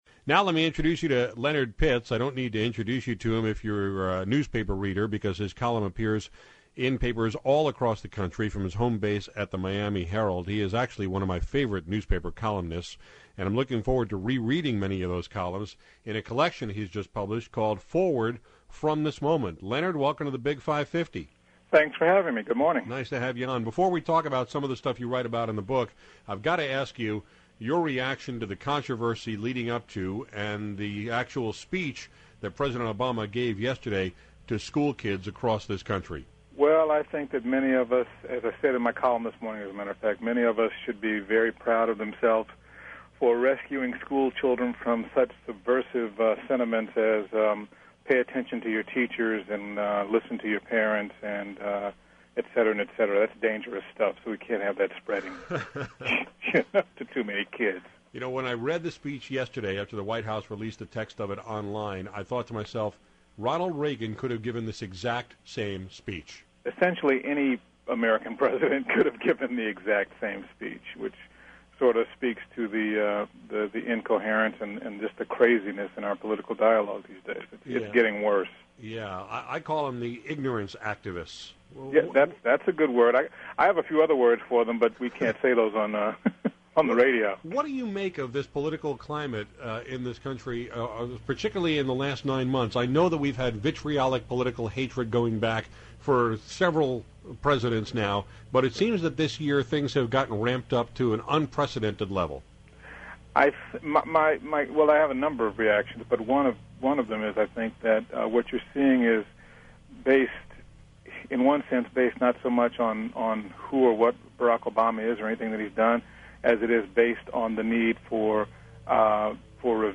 Leonard Pitts is one of my favorite newspaper columnists, so I was happy to talk with him on KTRS/St. Louis this morning.